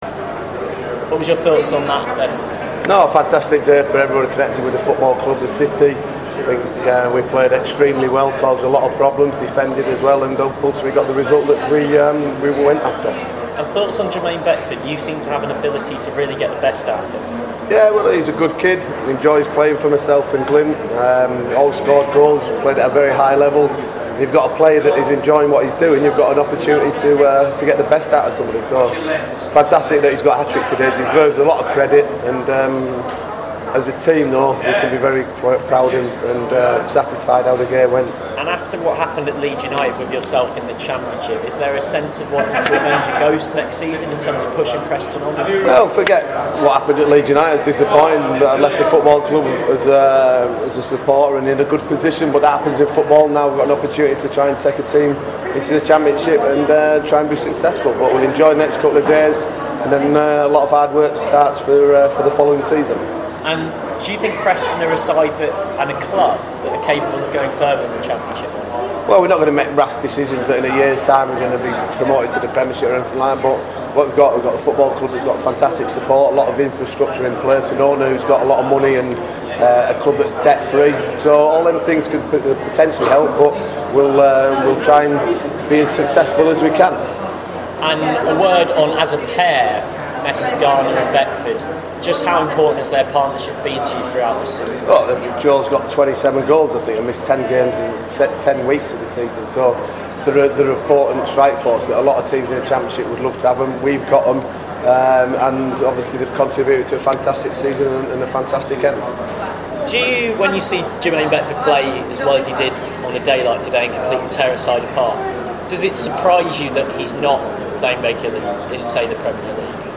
Simon Grayson interview after Preston are promoted to the Championship